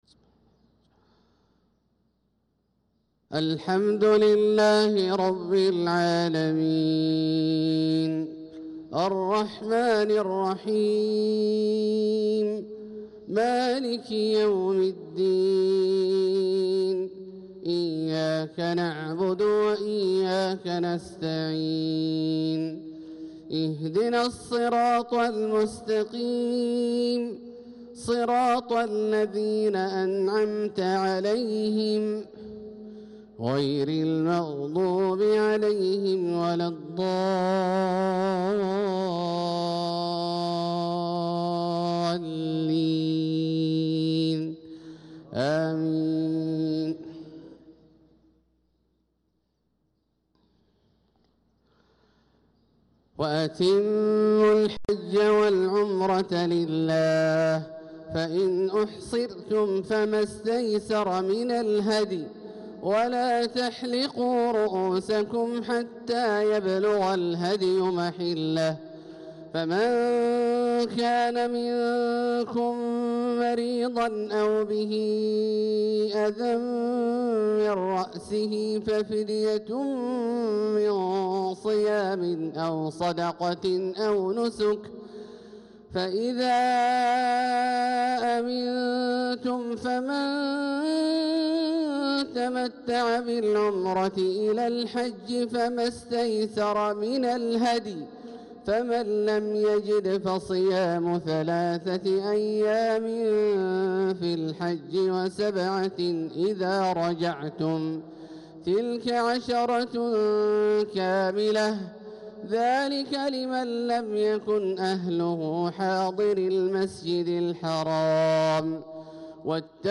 صلاة الفجر للقارئ عبدالله الجهني 7 ذو الحجة 1445 هـ
تِلَاوَات الْحَرَمَيْن .